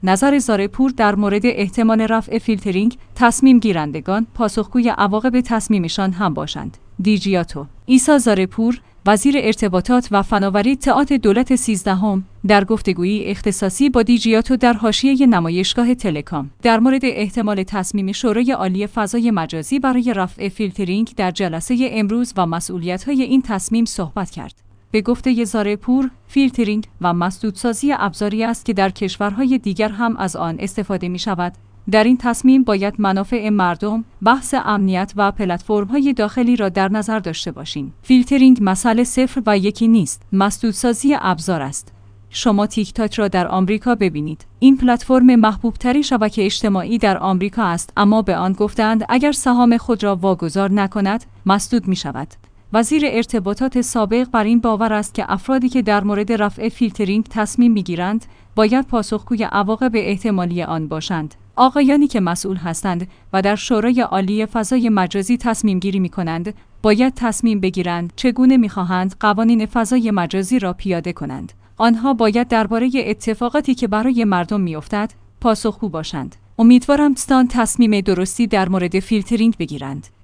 دیجیاتو/ «عیسی زارع‌پور»، وزیر ارتباطات و فناوری اطلاعات دولت سیزدهم، در گفتگویی اختصاصی با دیجیاتو در حاشیه نمایشگاه تلکام، درمورد احتمال تصمیم شورای عالی فضای مجازی برای رفع فیلترینگ در جلسه امروز و مسئولیت‌های این تصمیم صحبت کرد.